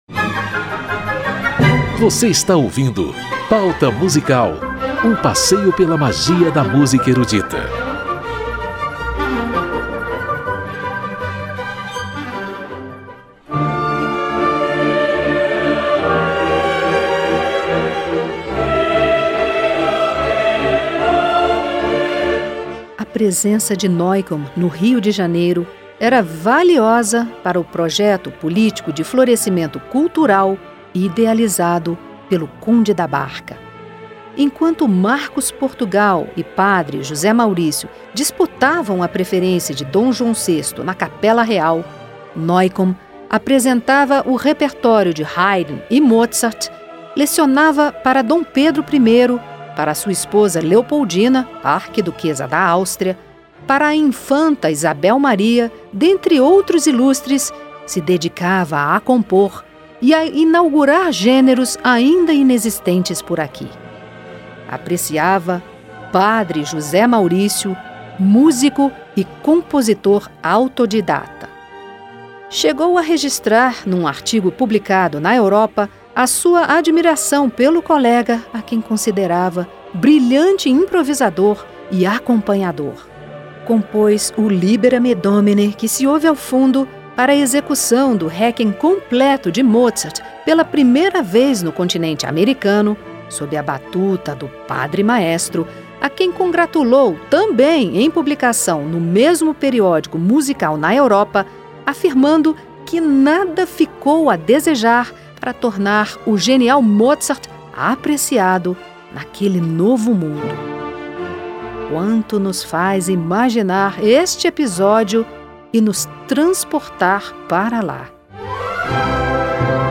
Na Corte de D. João VI, um austríaco em missão no Brasil cravou um marco na história de nossa música ao criar repertório de câmara, compor a primeira sinfonia escrita em solo brasileiro, misturar clássico e popular e inspirar linhas melódicas para o Hino Nacional Brasileiro. Em duas edições, cantores e instrumentistas brasileiros interpretam modinhas cariocas e obras de Sigismund Neukomm.